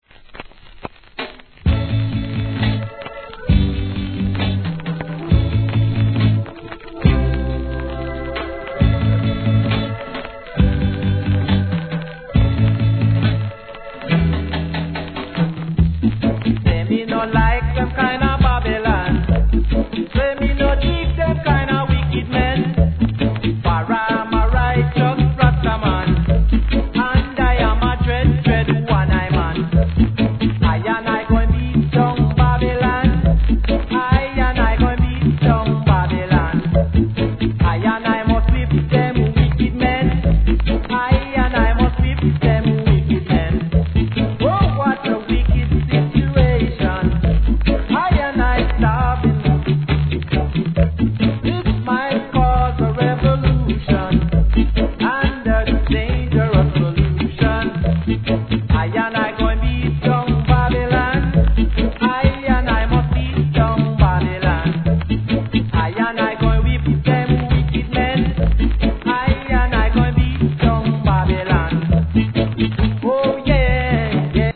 関連カテゴリ REGGAE